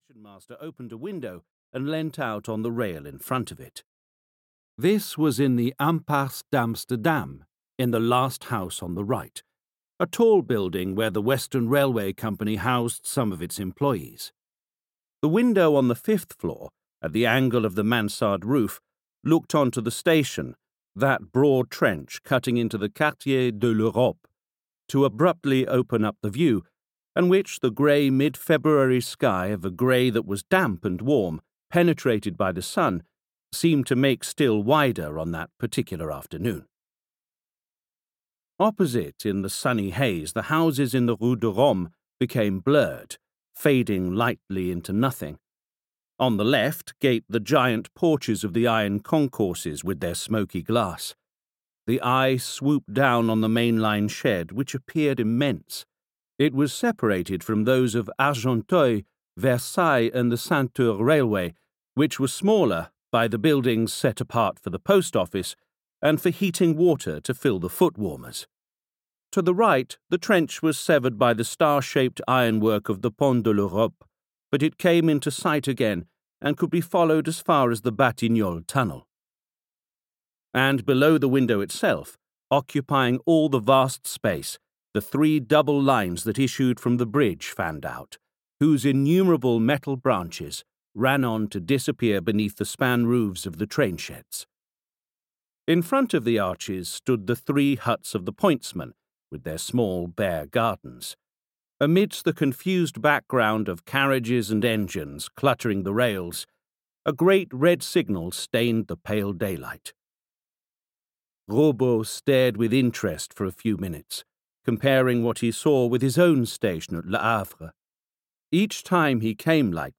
La Bête Humaine (EN) audiokniha
Ukázka z knihy